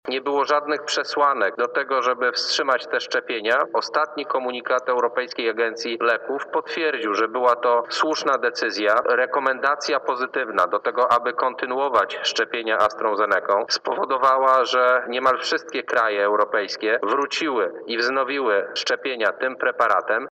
Polski rząd nie uległ panice – mówi Szef Kancelarii Premiera Michał Dworczyk: